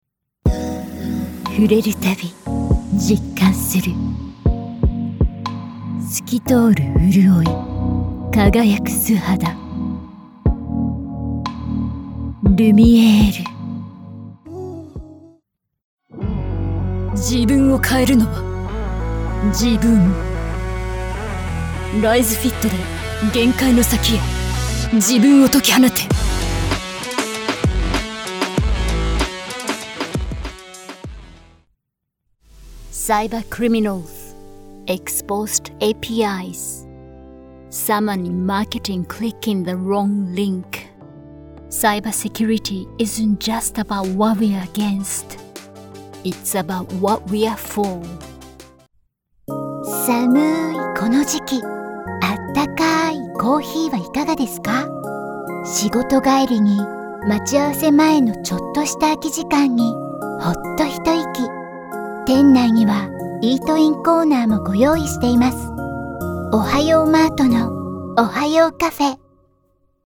Commercial – Japanese & English | Versatile & Authentic
Neutral international or Japanese-accented English
• Neumann TLM 103 condenser microphone